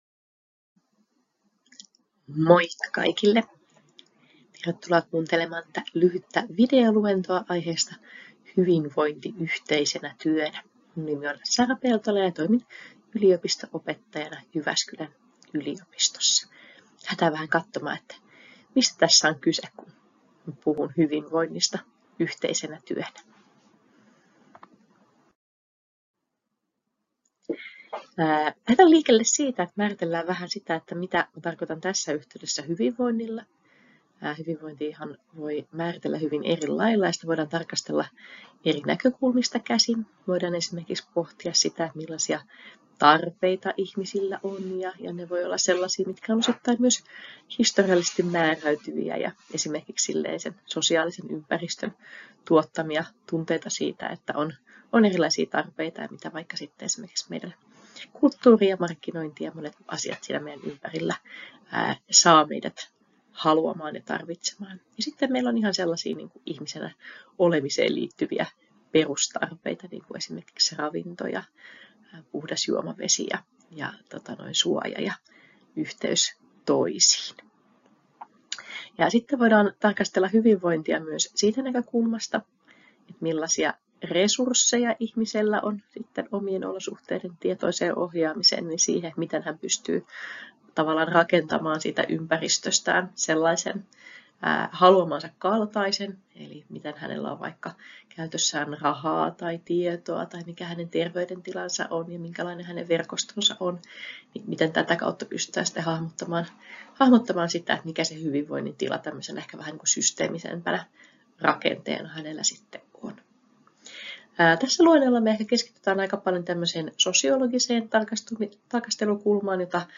Monialaiseen verkostotyöhön liittyvä luento hyvinvoinnista yhteisenä työnä